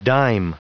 Prononciation du mot dime en anglais (fichier audio)
Prononciation du mot : dime